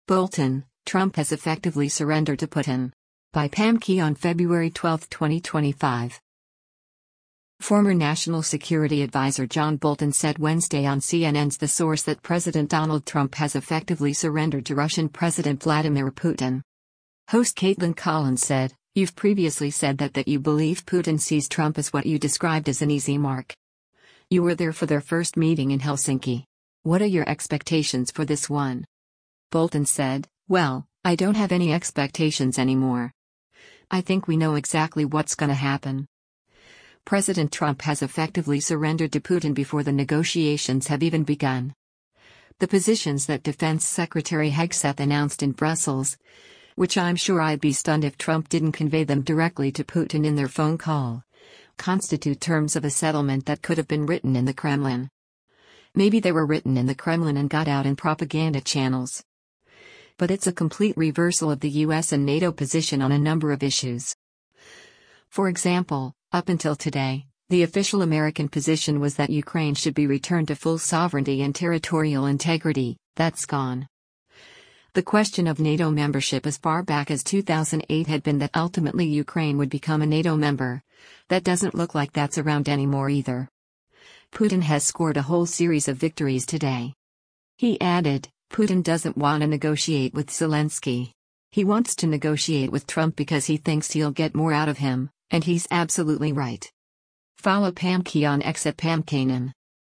Former National Security Advisor John Bolton said Wednesday on CNN’s “The Source” that President Donald Trump “has effectively surrendered” to Russian President Vladimir Putin.